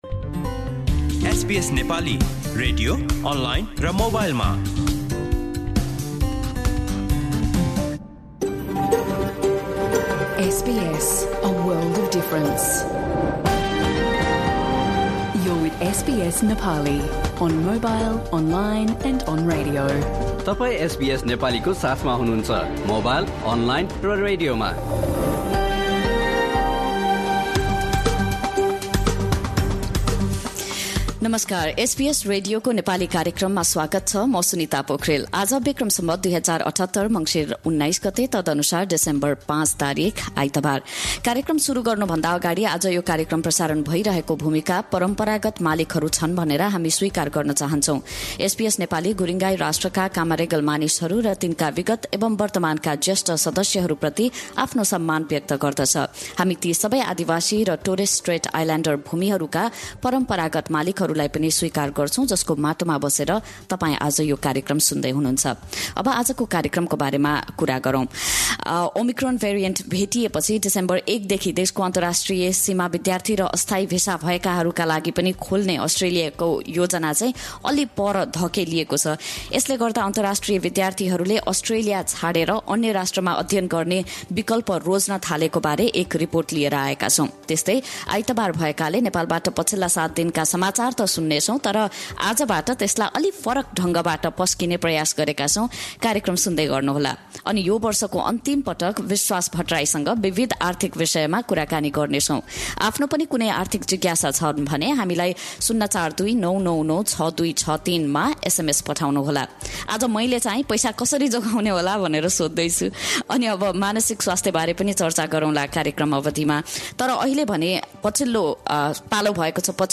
एसबीएस नेपाली रेडियो कार्यक्रम: आइतवार ६ डिसेम्बर २०२१